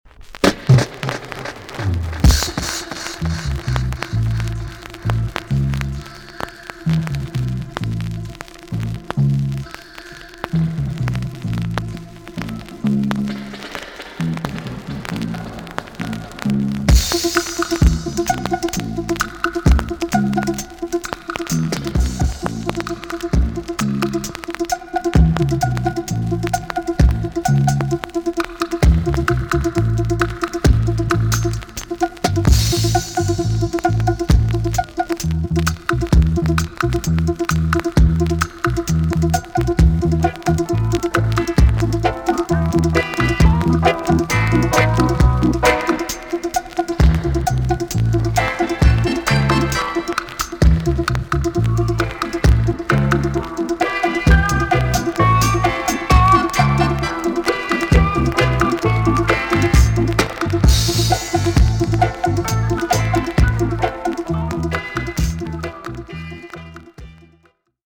TOP >REGGAE & ROOTS
B.SIDE Version
VG ok チリノイズがあります。